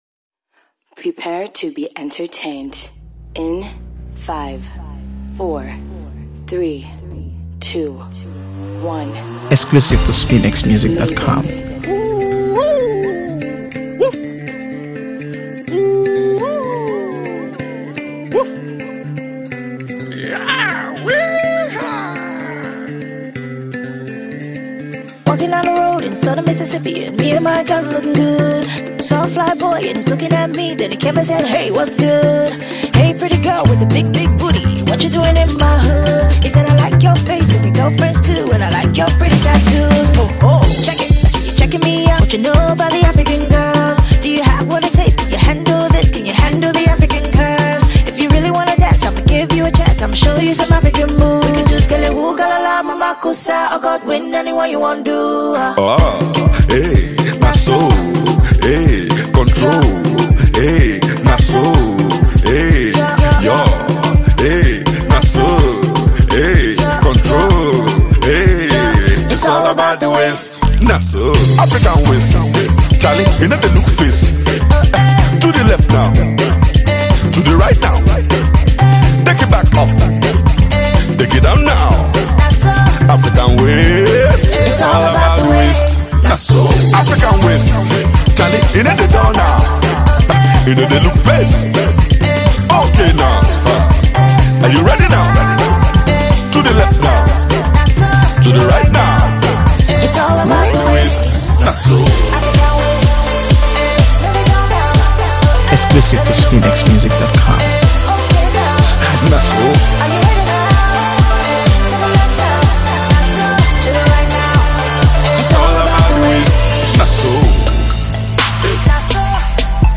AfroBeats | AfroBeats songs
This song is a perfect blend of rhythm and melody